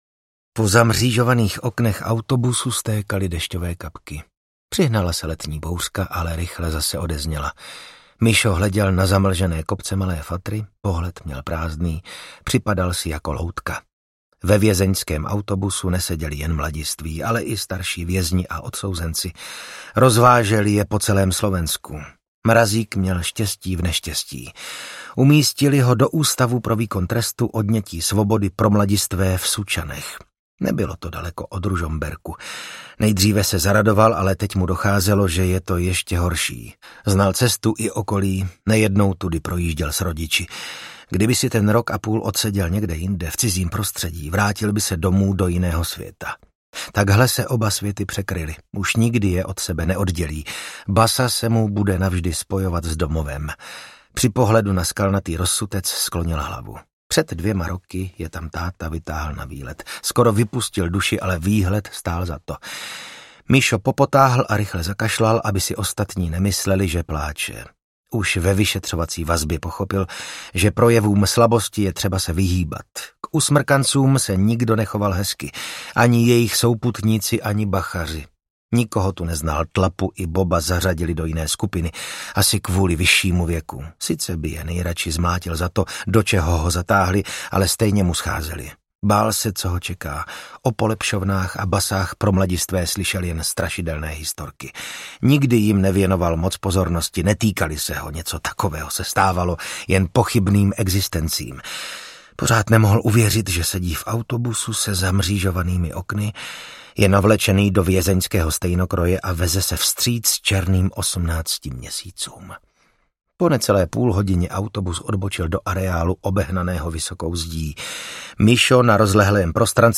Černá hra audiokniha
Ukázka z knihy
| Vyrobilo studio Soundguru.
• InterpretVasil Fridrich